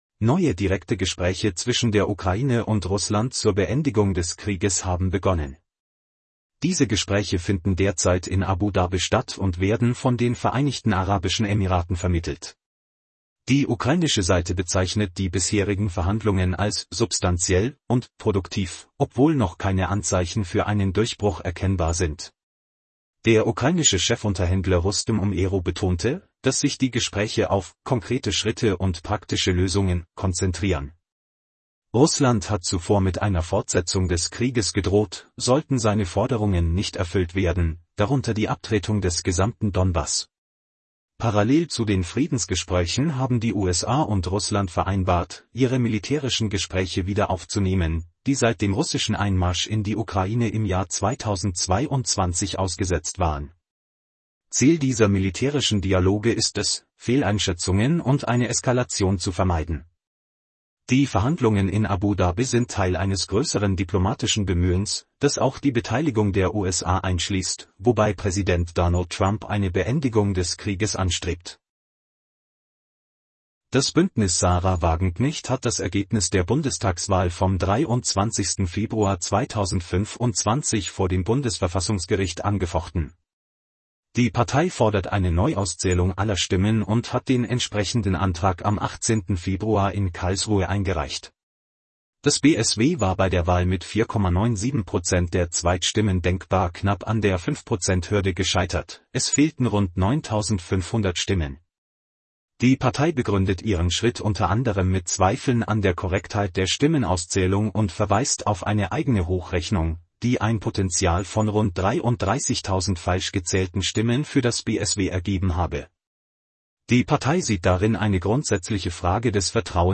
Dies ist ein Nachrichten-Podcast aus Dutzenden von Kurzberichten, zum hands-free Hören beim Autofahren oder in anderen Situationen.